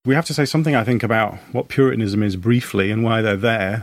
/ðe/ /ðeə/